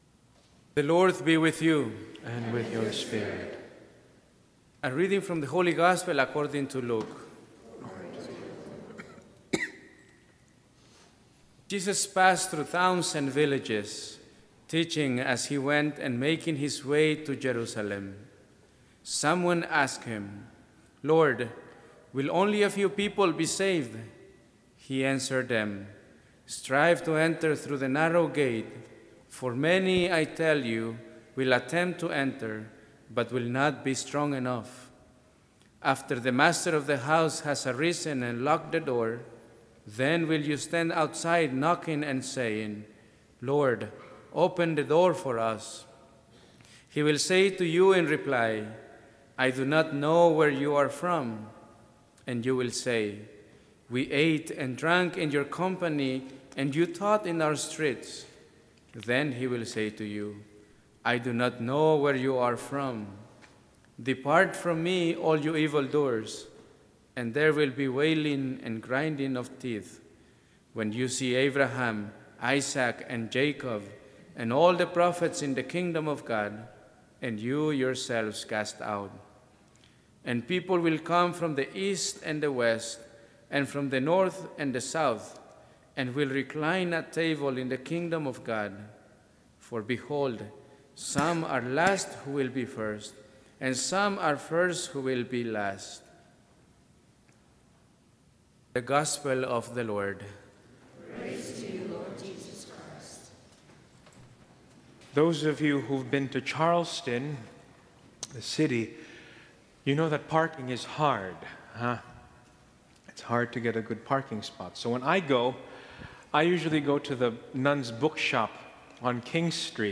Homily for Sunday August 25, 2019